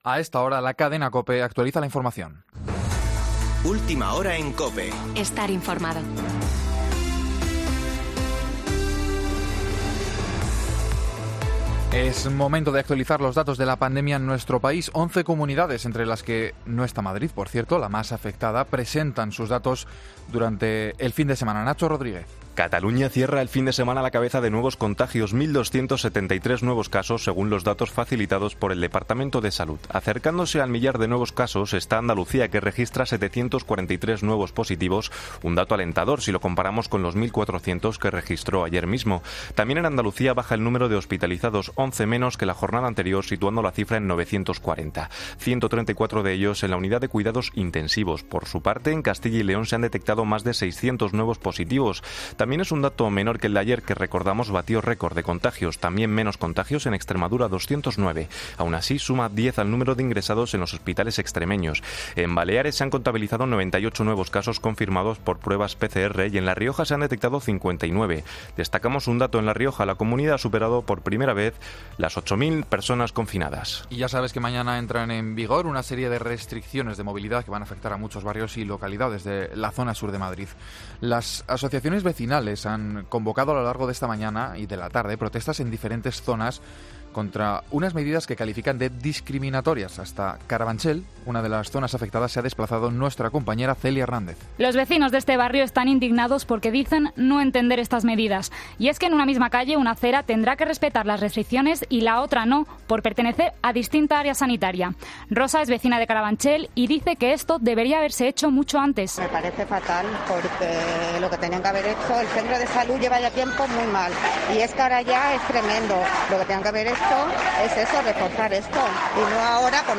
Boletín de noticias de COPE del 20 de septiembre de 2020 a las 18.00 horas